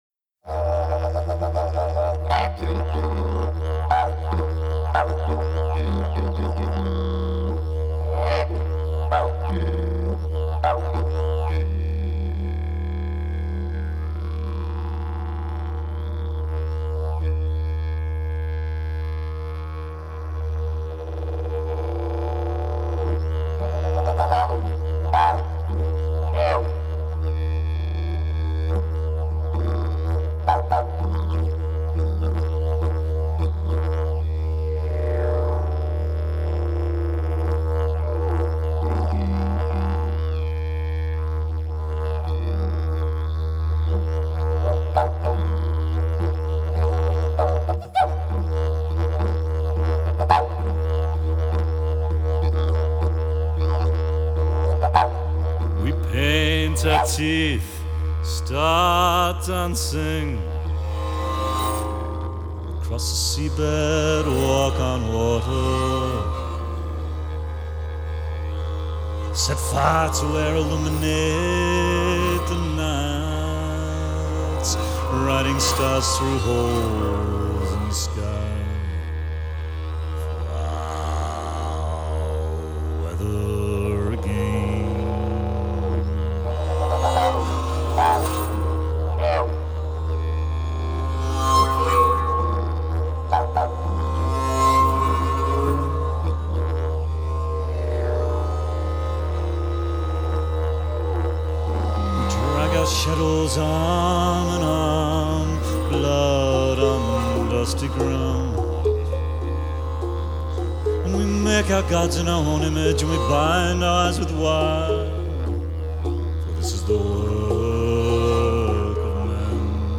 Australasian flute, mouthbows and vocals